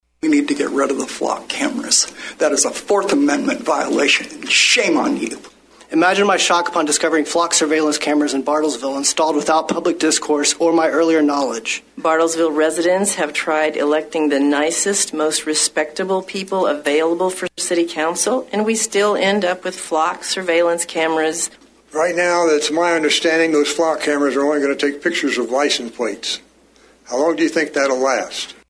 Many Bartlesville residents attended Monday's Bartlesville City Council meeting to speak out against the use of the cameras.